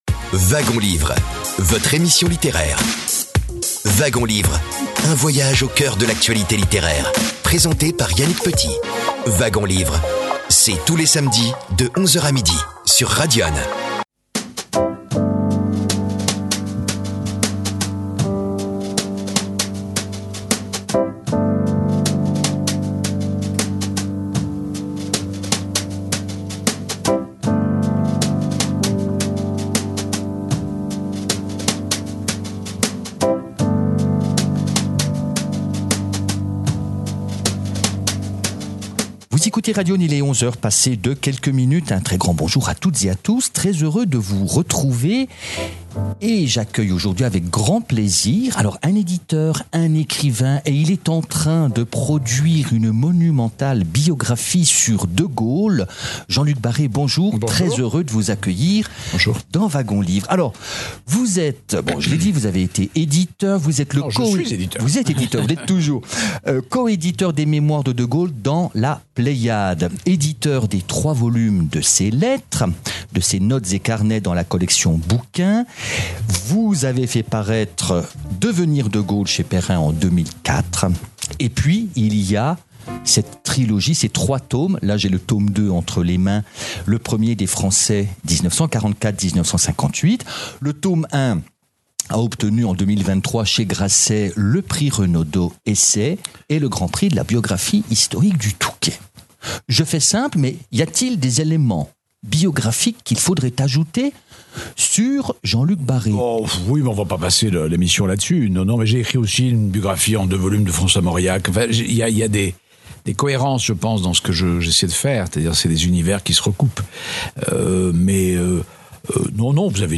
Entretien avec Jean-Luc BARRÉ pour le tome 2 〈Le premier des Français (1944-1958)〉 de sa biographie « De Gaulle, une vie » (Ed. Grasset).
Cette émission a été enregistrée, le 30 janvier 2026, au Grand Hôtel de la Cloche, à Dijon, alors que Jean-Luc Barré était l’invité du Club des Écrivains de Bourgogne.